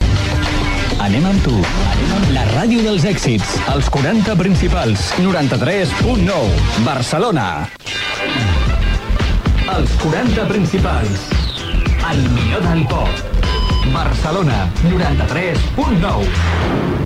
Indicatiu i freqüència de l'emissora